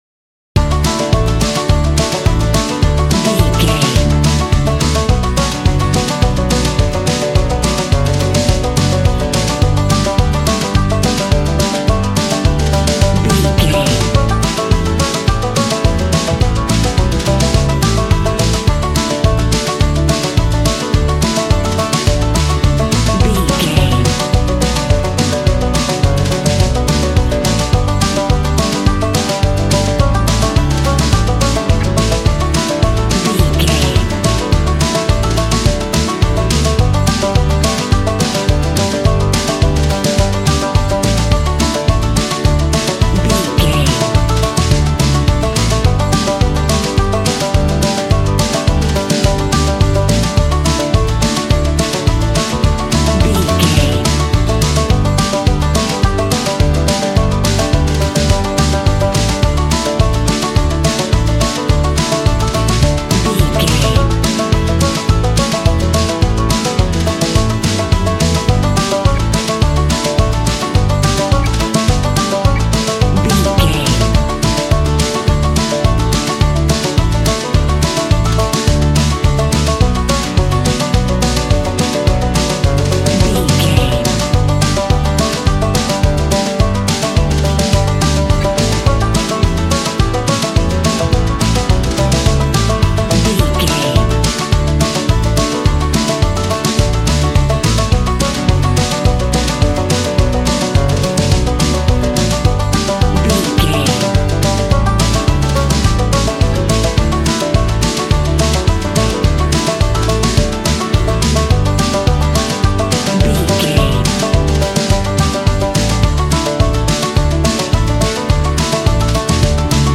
Fun and upbeat American fiddle music from the country.
Ionian/Major
bouncy
double bass
drums
acoustic guitar